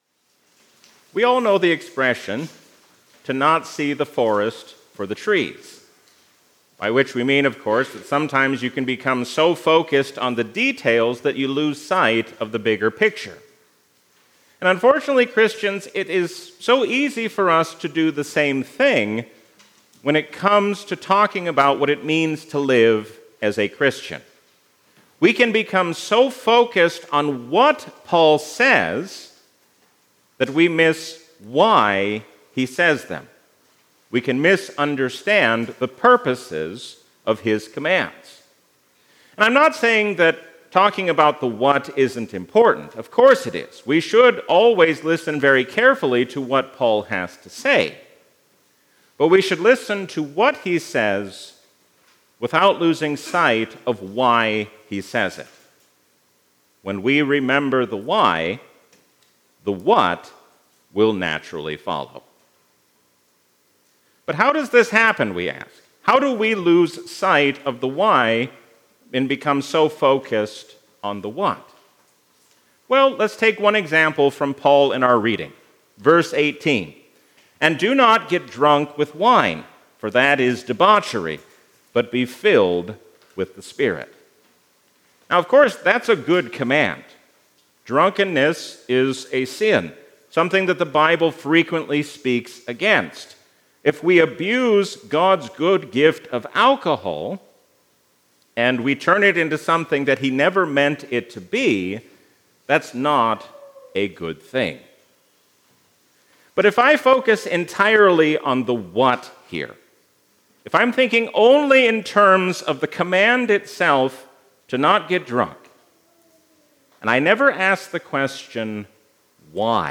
A sermon from the season "Easter 2025." Faith is not just what we know to be true, but how we respond to that truth.